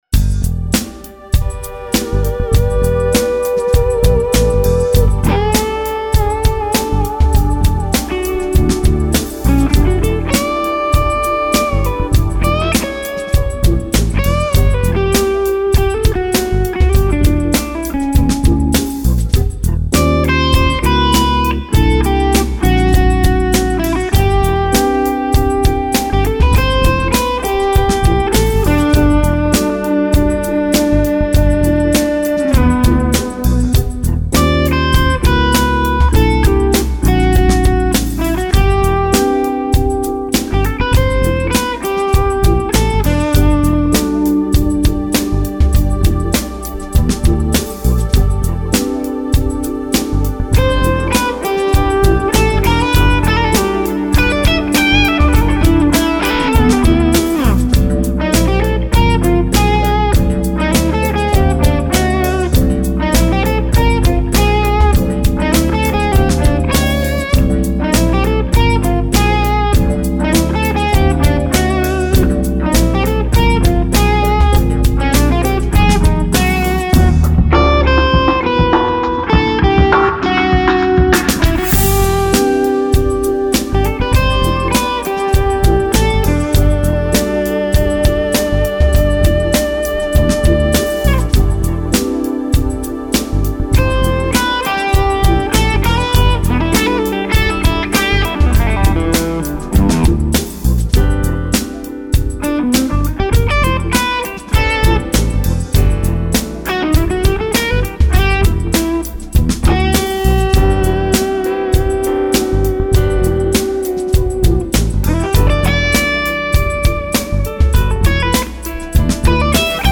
These clips are of my Fuchs ODS 100.